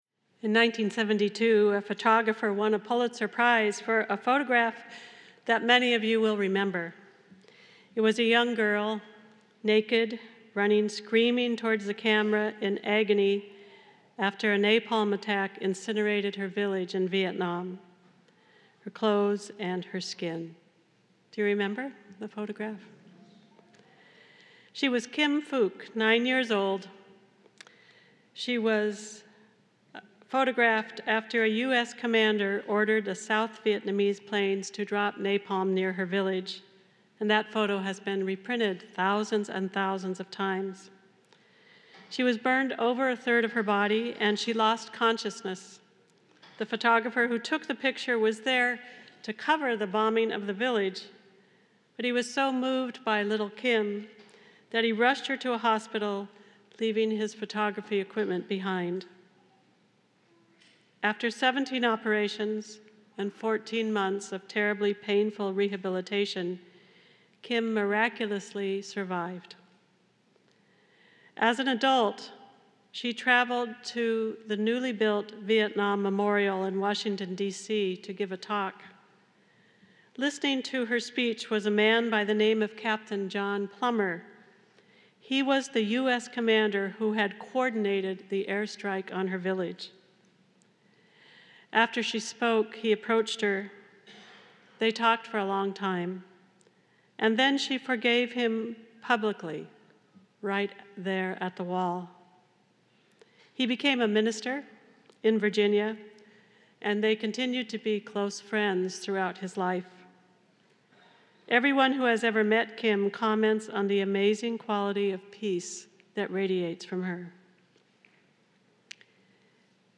Palm Sunday Mass